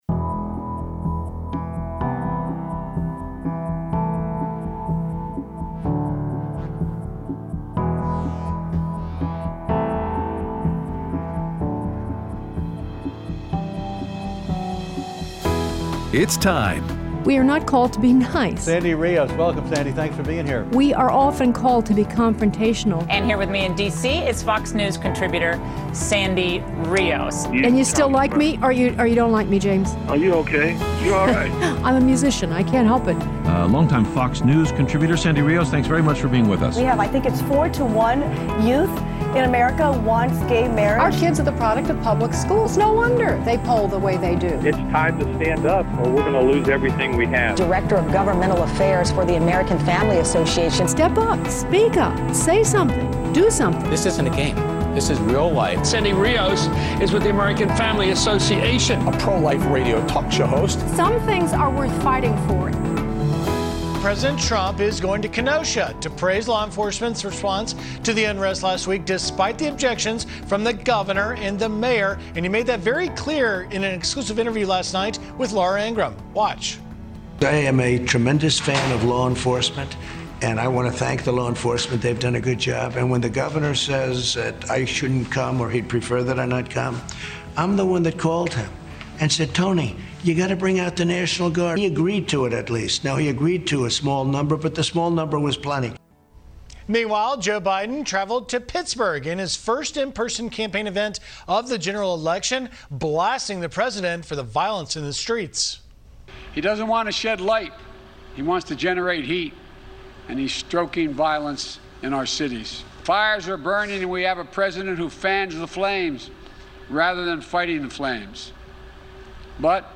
Interview with Star Parker About The Spiritual War Taking Place In America